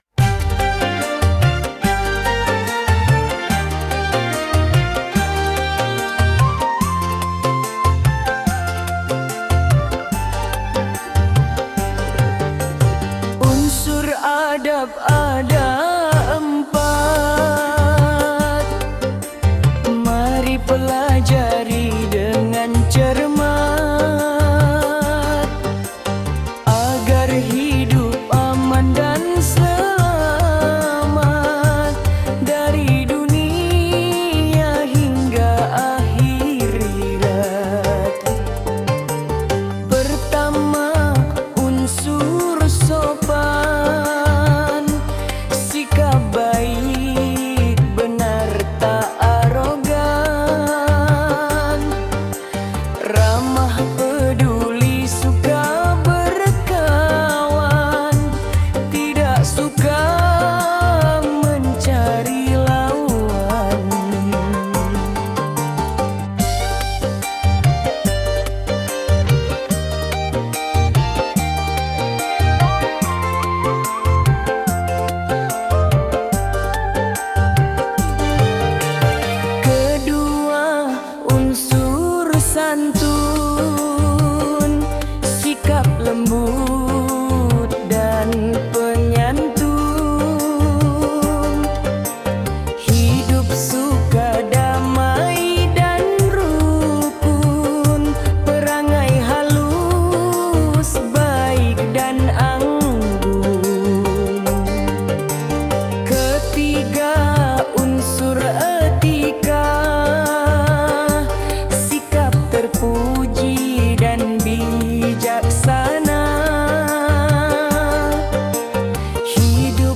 Dengarkan lagu dan musik syahdu dari syair tersebut di bawah ini